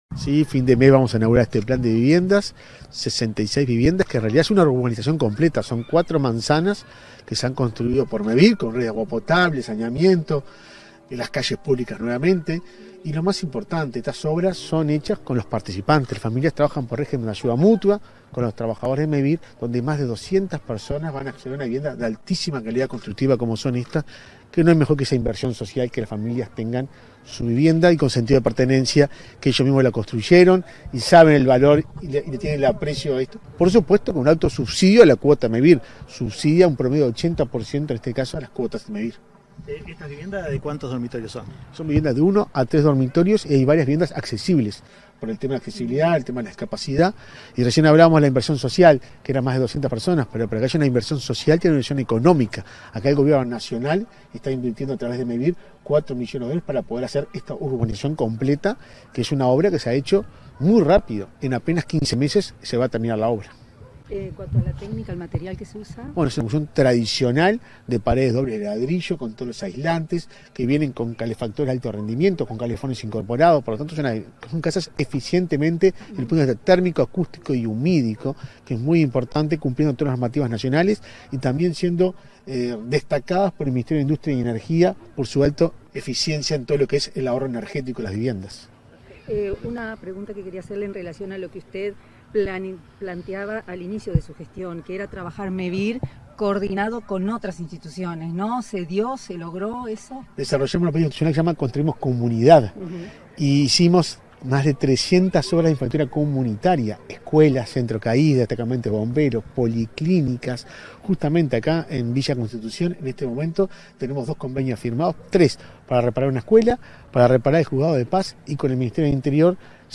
Declaraciones del presidente de Mevir, Juan Pablo Delgado
Declaraciones del presidente de Mevir, Juan Pablo Delgado 05/09/2024 Compartir Facebook X Copiar enlace WhatsApp LinkedIn Tras la recorrida y encuentro con los participantes del plan de viviendas en Villa Constitución, este 5 de setiembre, el presidente de Mevir, Juan Pablo Delgado, realizó declaraciones a la prensa.